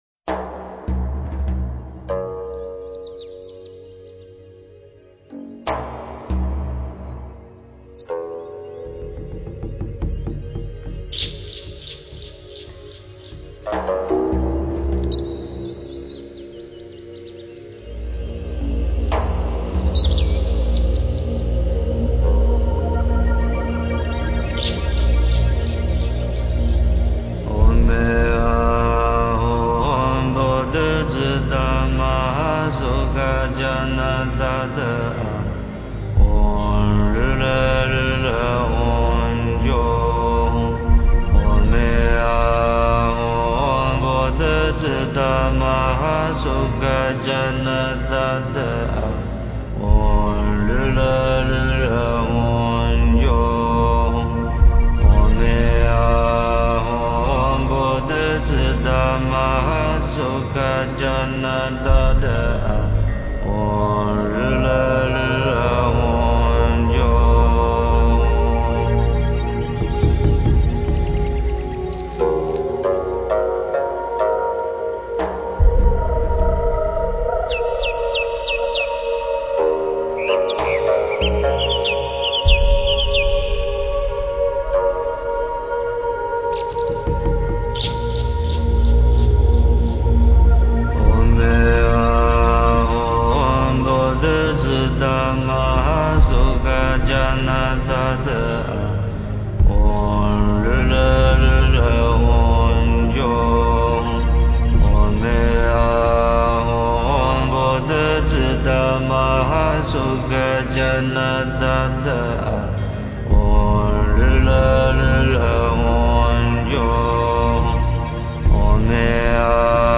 佛音 诵经 佛教音乐 返回列表 上一篇： 大悲咒(梵音